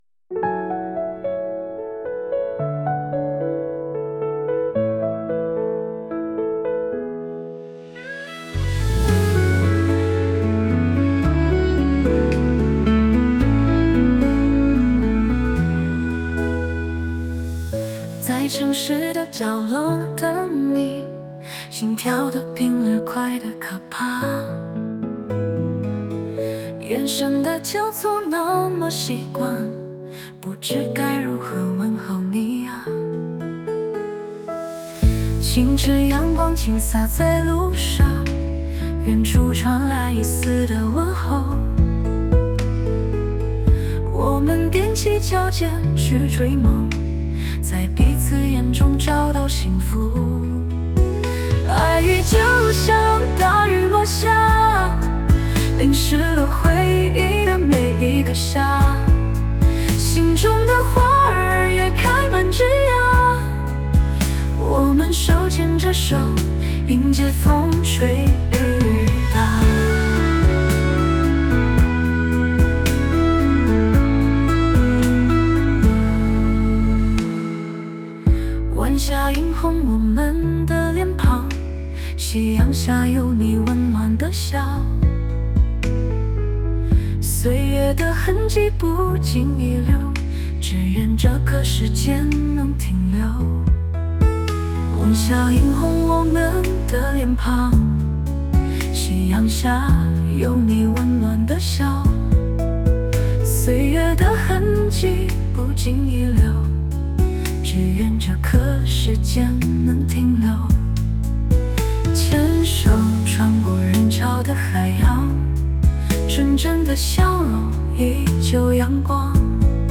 人工智能生成式歌曲